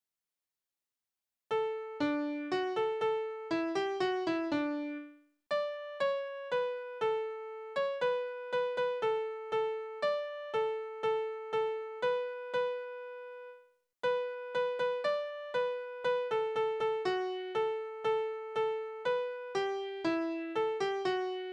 Trinklieder: Es stand ein Wirtshaus wohl an dem Rhein
Tonart: D-Dur
Taktart: 4/4
Tonumfang: Oktave